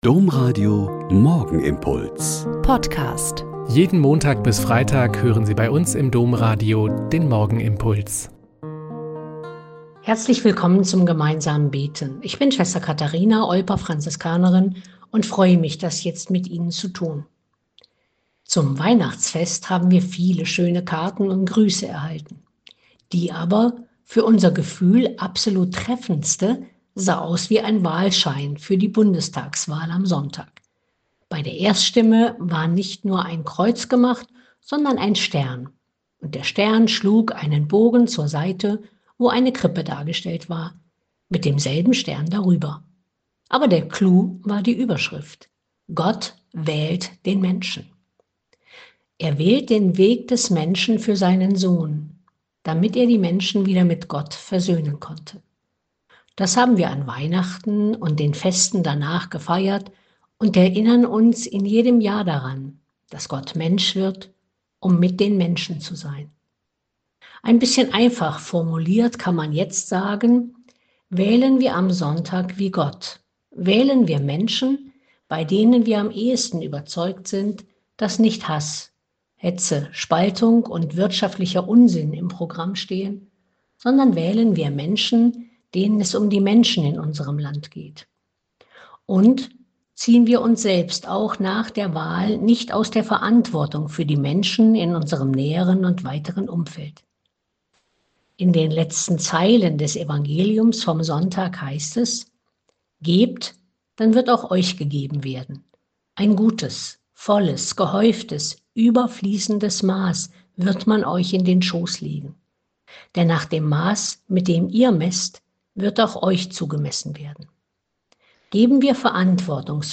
Mk 8,34-9,1 - Gespräch